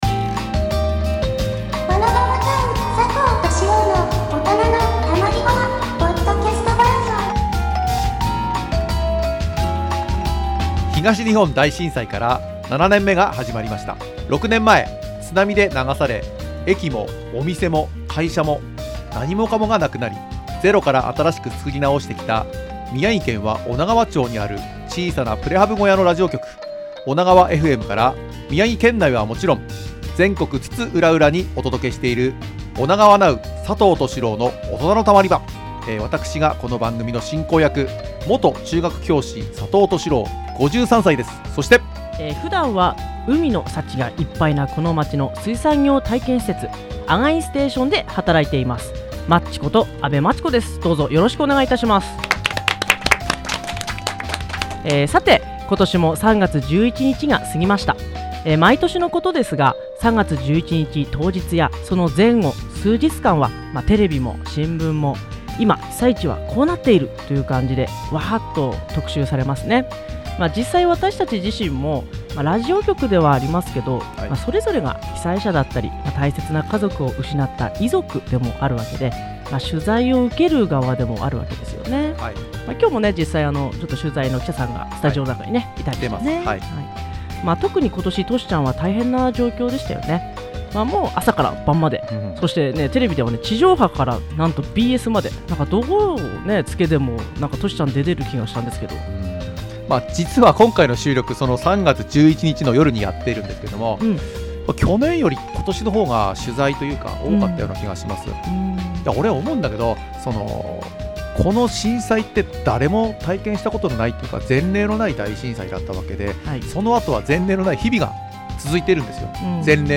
女川町民はもちろん、ゆかりの人々をご紹介していくインタビューコーナー「この人さ聞いてみっちゃ」。 今週は30分まるまるのスペシャル版としてお送りします。
このポッドキャストでは、宮城・TBCラジオで放送した内容を再編集・構成してお届けします。
なお著作権の関係で、音楽やテーマ音楽につきましては、一部の曲、BGMなどをカットしたり、差し替えております。